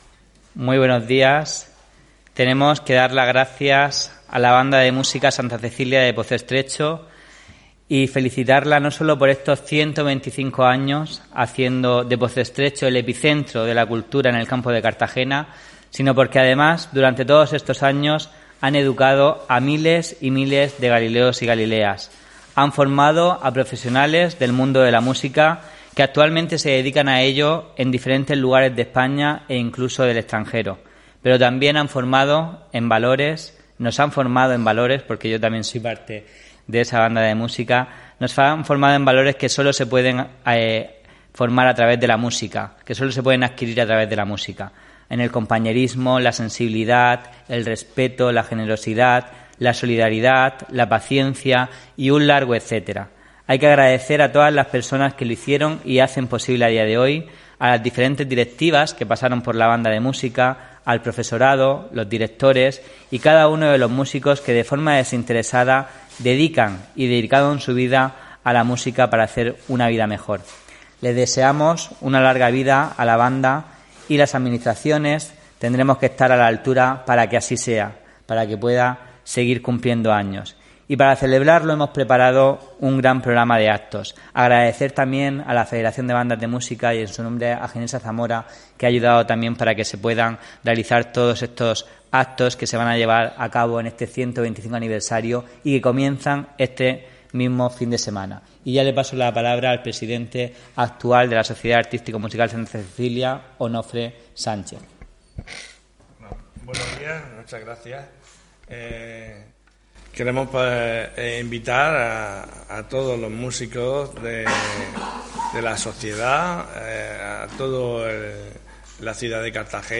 Presentación de las actividades por el 125 aniversario de la Sociedad Artístico Musical Santa Cecilia de Pozo Estrecho